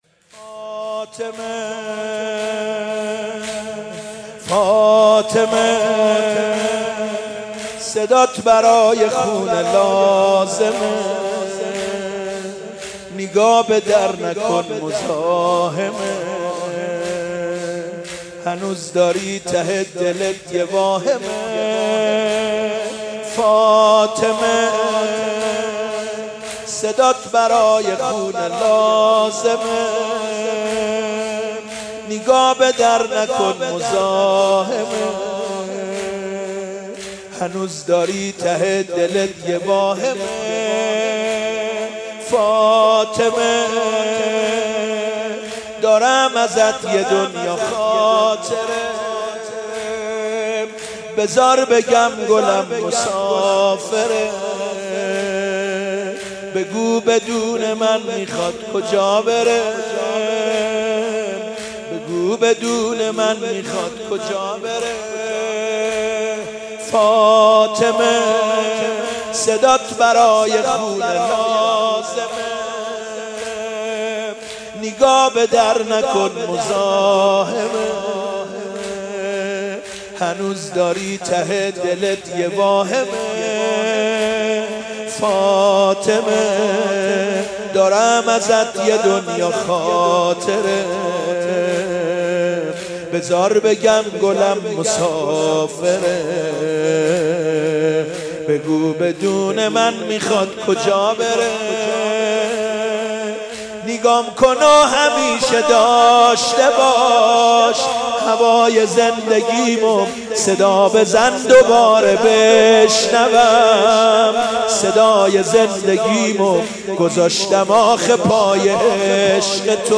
فاطمیه 96 - اردبیل - زمینه - فاطمه صدات برای خونه لازمه